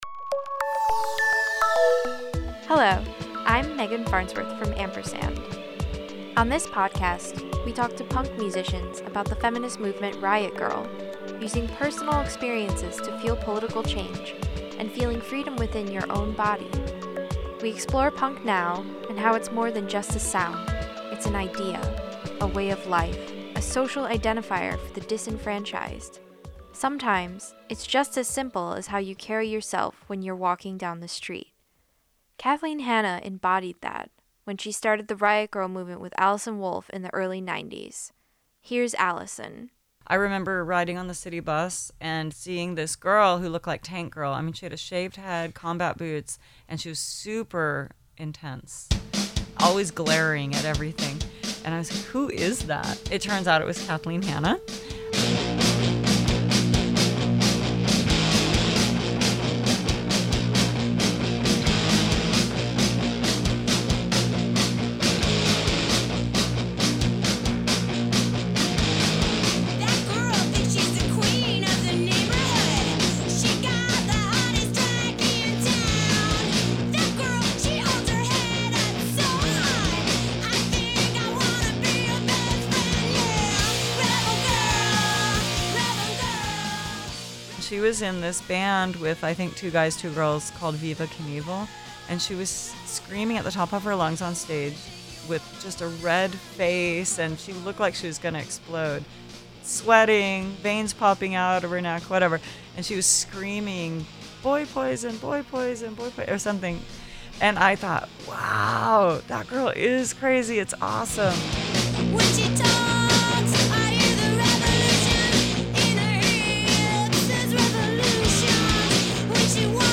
In this episode of Ampersand, we talk with Kathleen Hanna about her current band, the Julie Ruin, and how music is empowering.